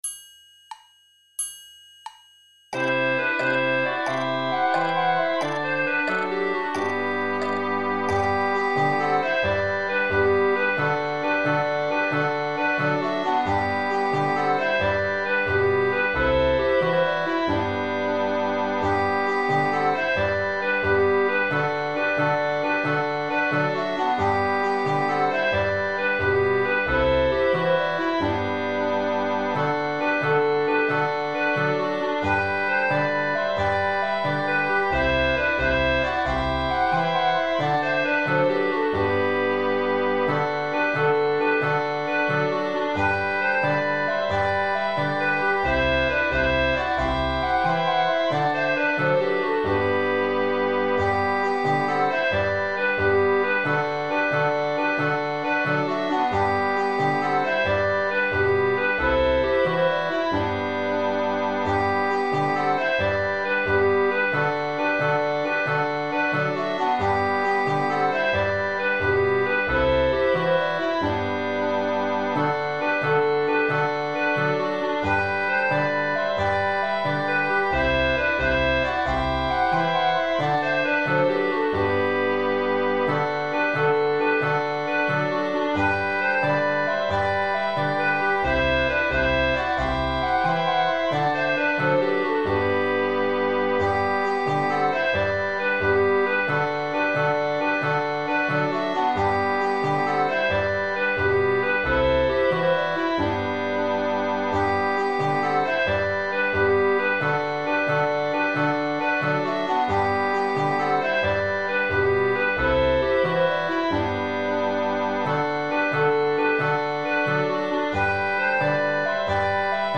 Standard Backing tracks [MP3]
Slow